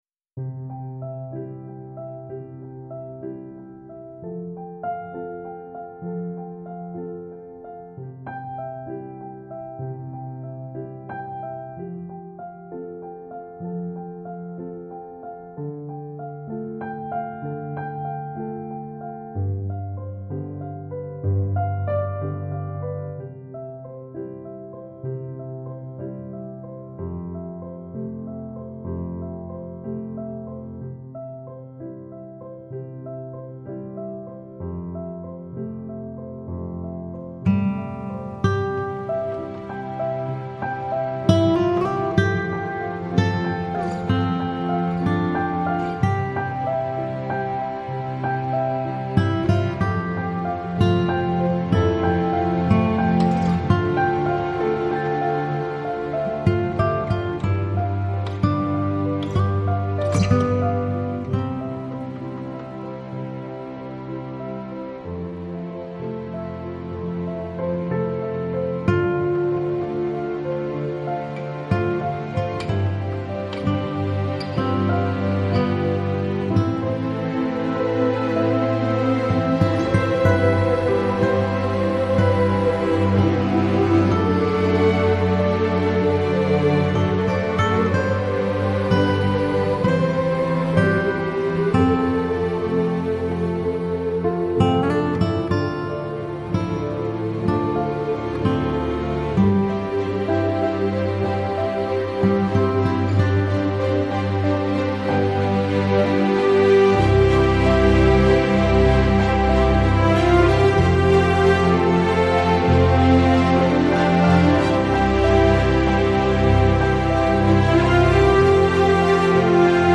Жанр: Modern Classical, Stage & Screen, Electronic